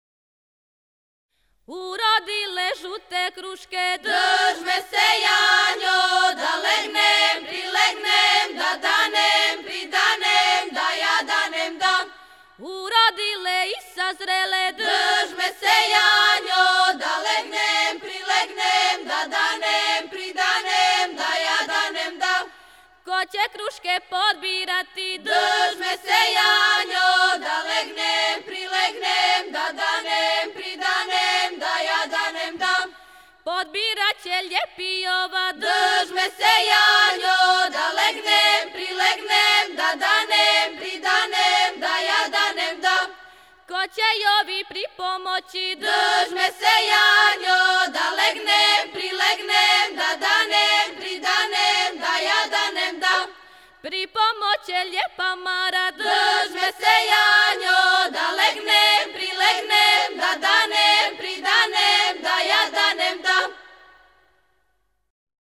Група певача